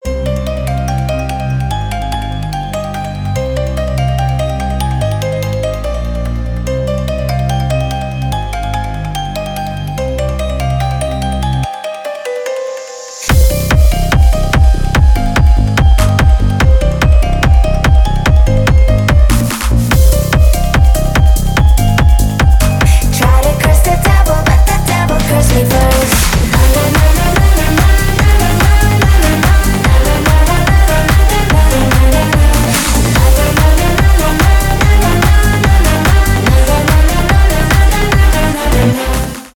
клубные
техно